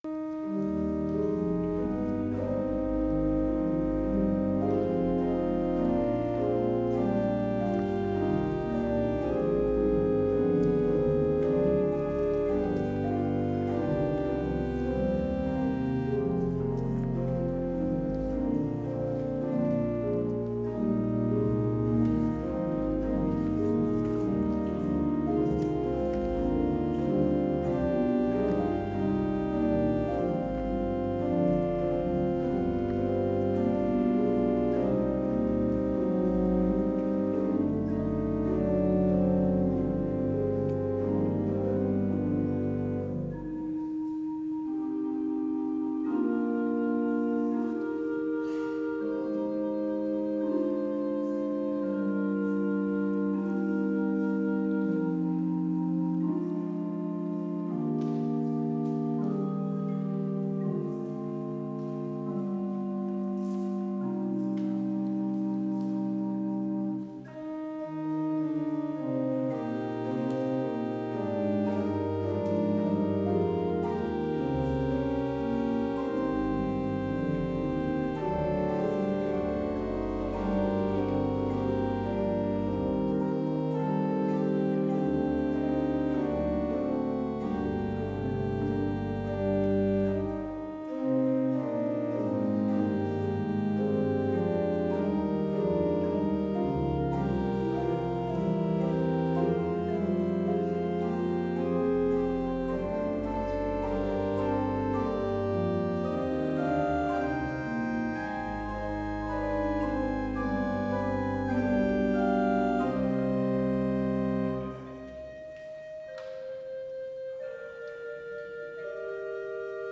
Complete service audio for Chapel - July 27, 2022